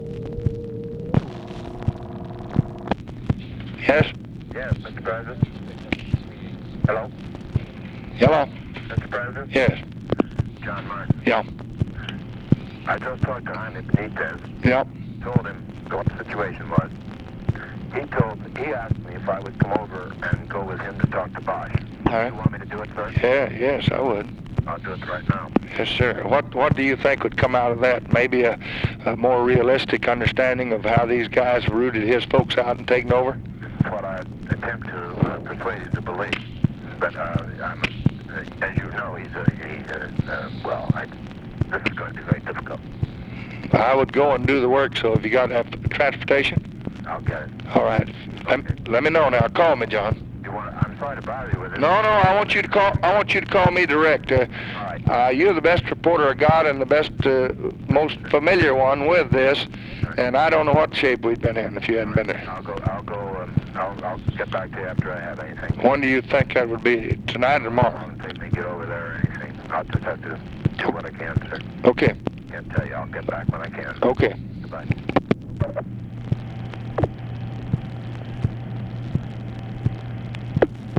Conversation with JOHN BARTLOW MARTIN, May 3, 1965
Secret White House Tapes